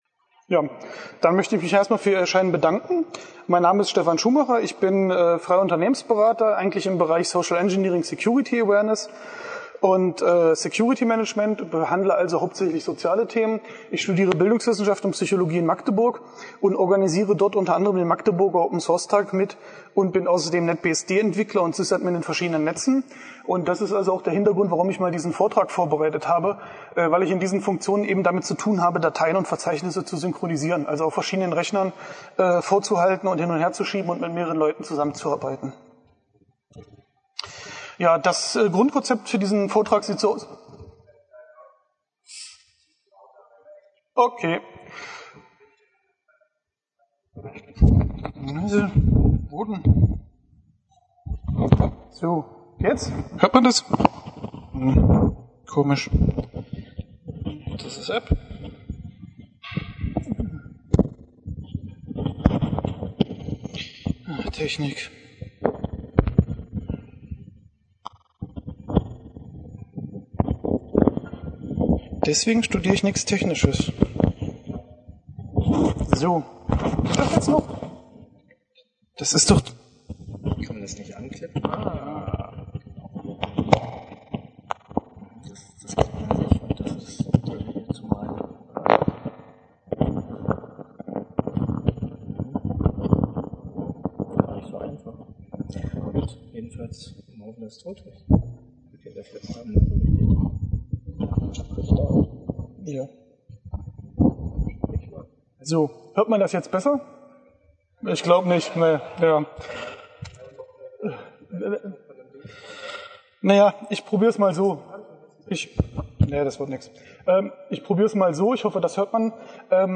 Die Chemnitzer Linux-Tage sind eine Veranstaltung rund um das Thema Linux und Open Source für jedermann, die Linux-Nutzer, Insider und Unternehmen zusammenbringt.
Dieser Vortrag beschreibt die Möglichkeiten Verzeichnisse zu synchronisieren. Es werden die grundlegenden Probleme der Synchronisation erläutert und verschiedene Programme, wie Rdist, Rsync, Rdiff-Backup und Unison vorgestellt und ihr Funktionsumfang beschrieben.